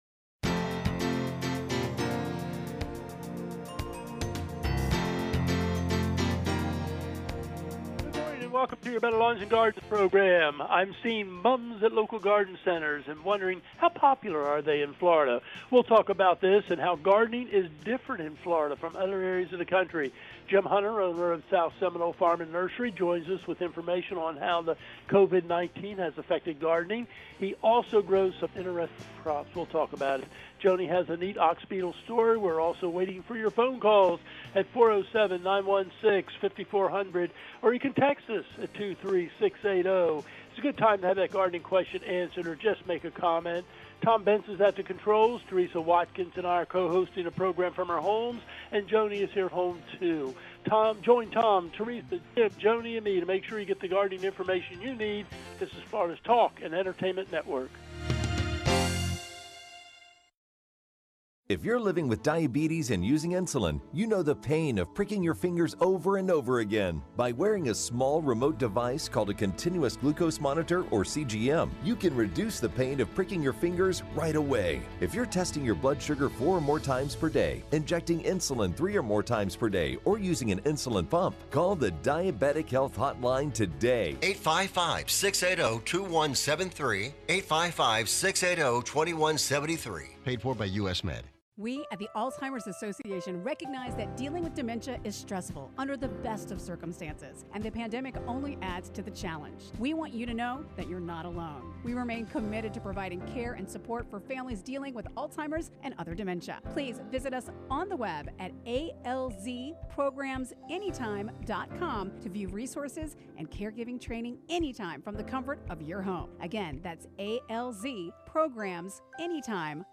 Gardening and text questions include how Florida gardening differs from other states, chrysanthemums, mango seedlings.